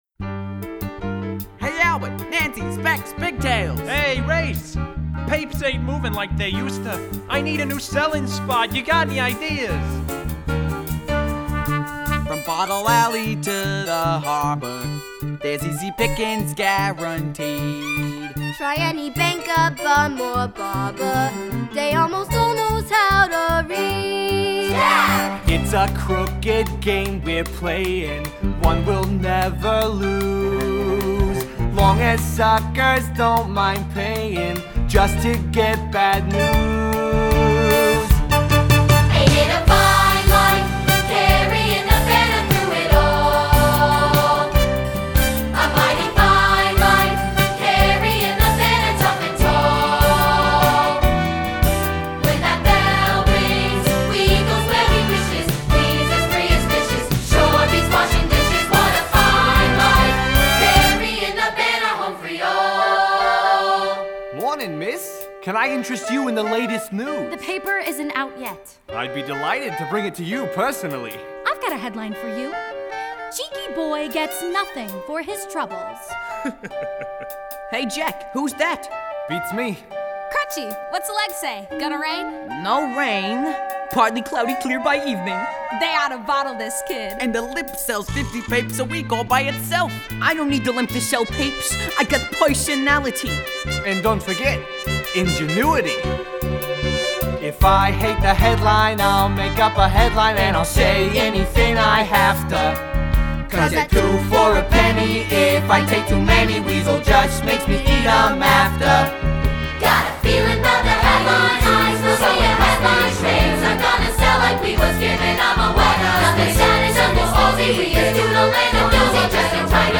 Guide Vocal Tracks: